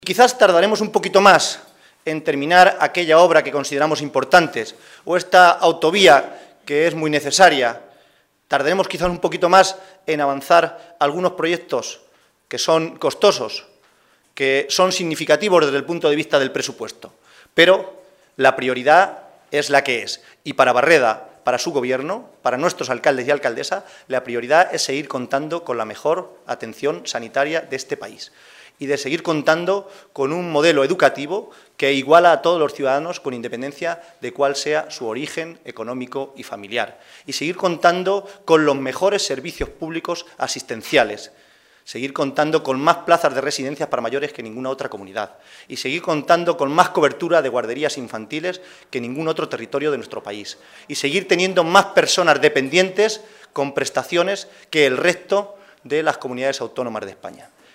El secretario de Organización del PSOE de C-LM, José Manuel Caballero, ha asegurado hoy, durante la celebración del Comité Regional de este partido, que “los socialistas de C-LM nos hemos conjurado para no dar ni un paso atrás en el mantenimiento y mejora de lo que más importa a los ciudadanos de nuestra Región, que es una sanidad y una educación pública de calidad, y una política social dirigida a los que más lo necesitan”.